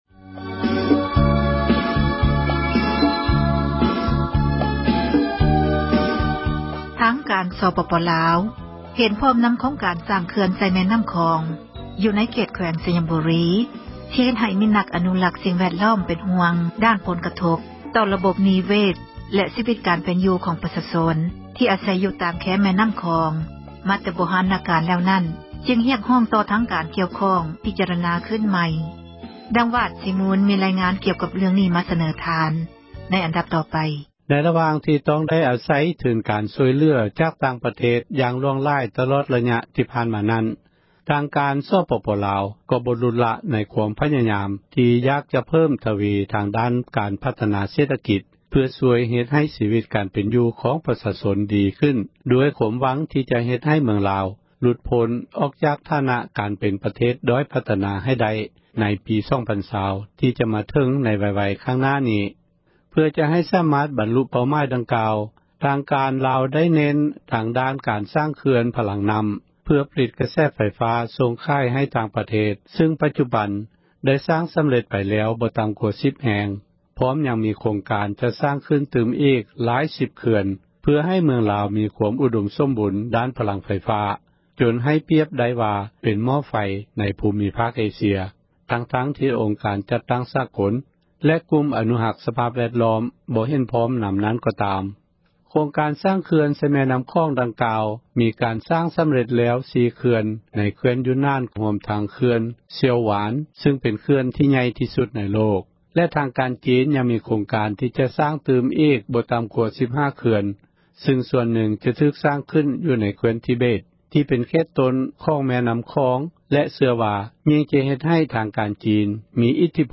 ມີຣາຍງານ ມາສເນີທ່ານ.